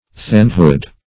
Definition of thanehood.
Search Result for " thanehood" : The Collaborative International Dictionary of English v.0.48: thanehood \thane"hood\ (th[=a]n"h[oo^]d), n. The character or dignity of a thane; also, thanes, collectively.